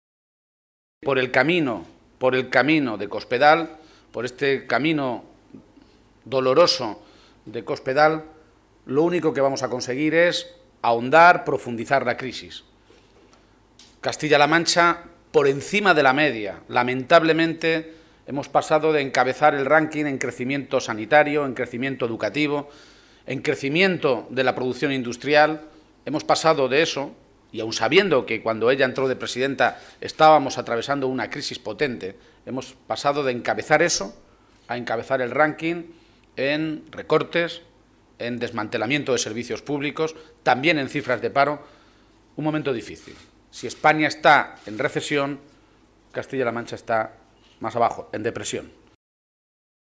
El secretario general del PSOE de Castilla-La Mancha, Emiliano García-Page, ha comparecido hoy ante los medios de comunicación minutos antes de la celebración del primer Comité Regional después de Décimo Congreso que le eligió con un 95 por ciento de los votos nuevo máximo dirigente de los socialistas de Castilla-La Mancha.